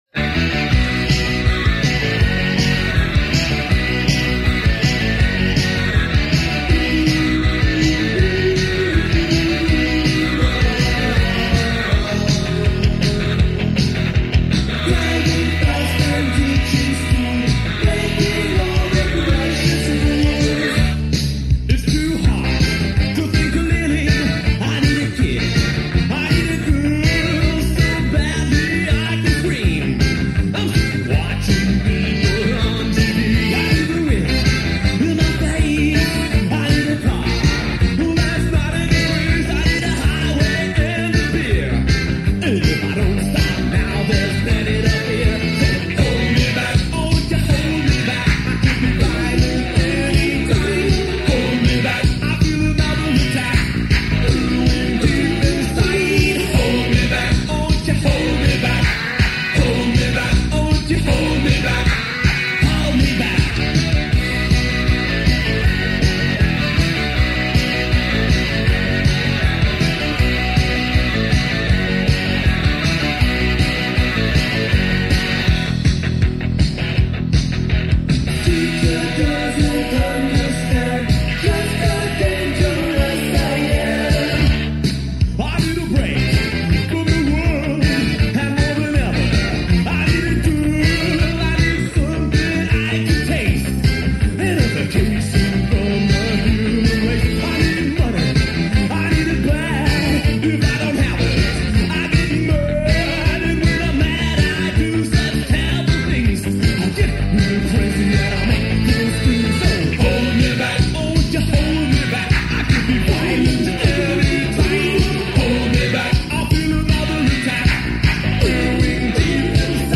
One of the best new wave bands of its time